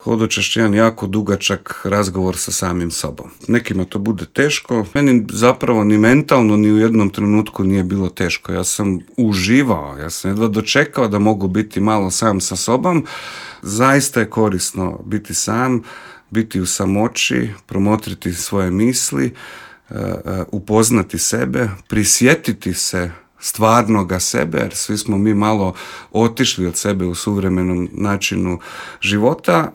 O karijeri, hodočašću i pronalasku sebe više je ispričao u intervjuu za Media Servis.